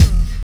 64BELLS-BD-R.wav